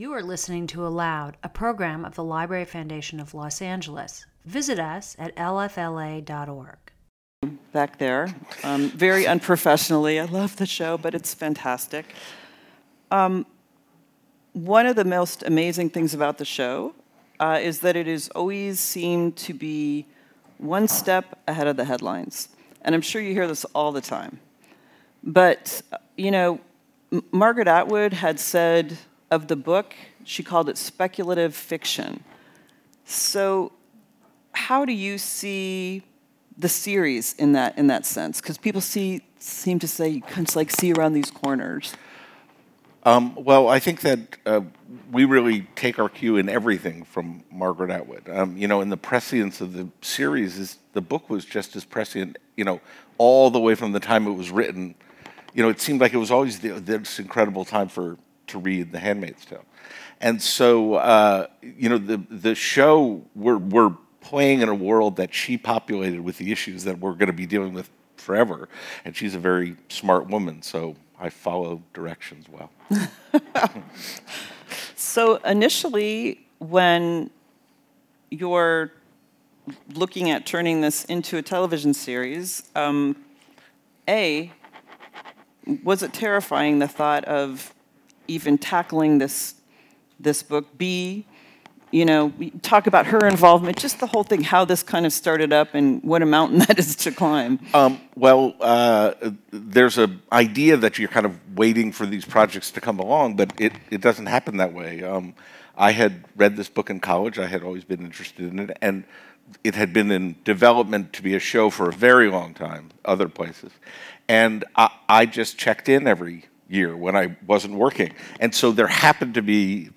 ALOUD is the Library Foundation of Los Angeles' award-winning literary series of live conversations, readings and performances at the historic Central Library and locations throughout Los Angeles.